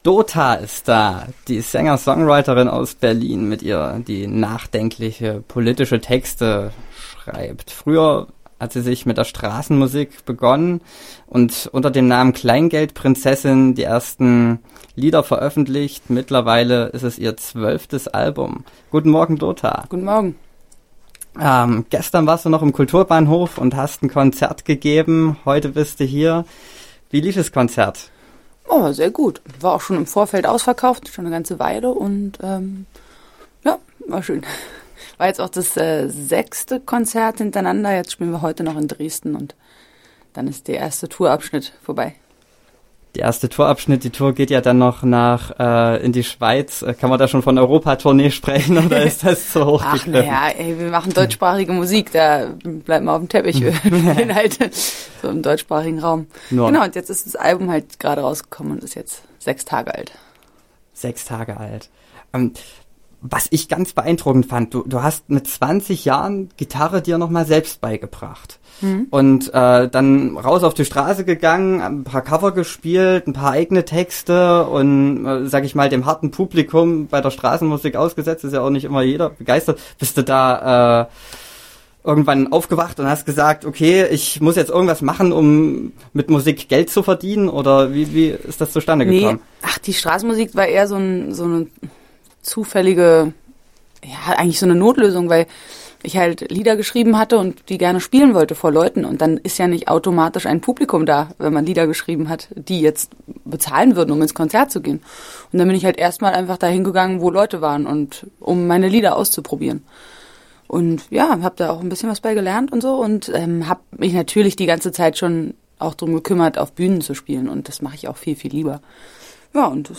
Im Studio: Dota